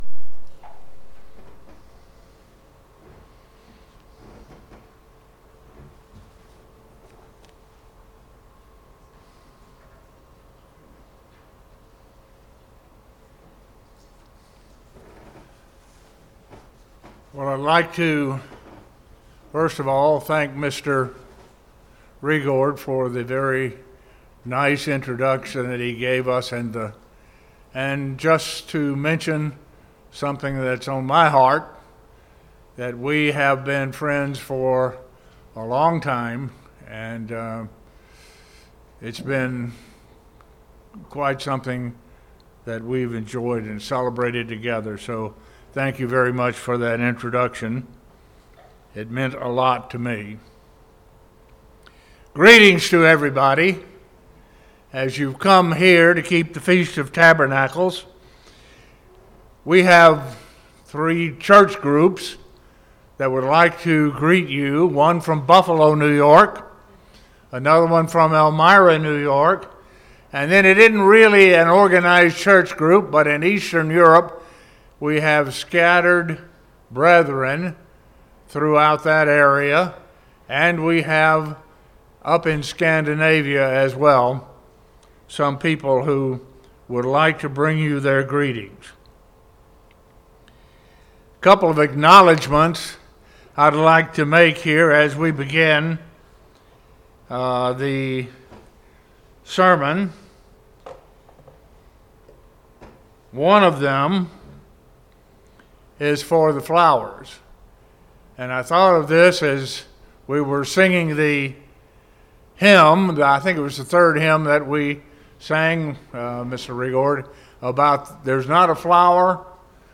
This sermon was given at the Anchorage, Alaska 2021 Feast site.